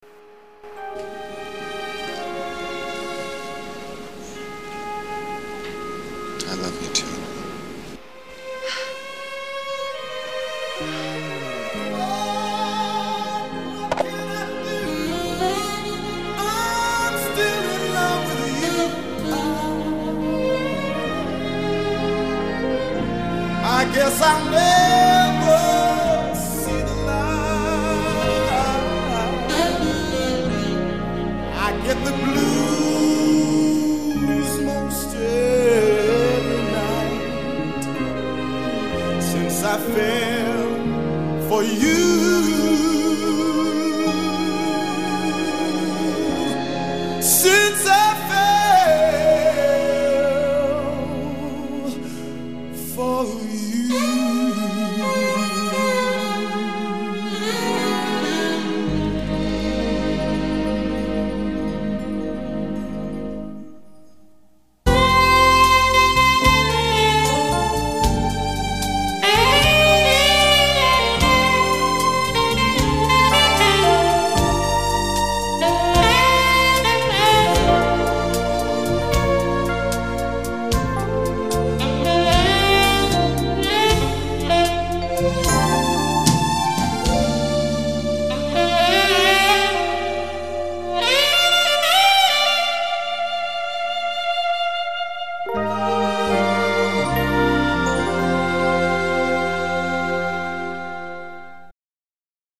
jazz standard
Sax
Vocals), recorded just for this episode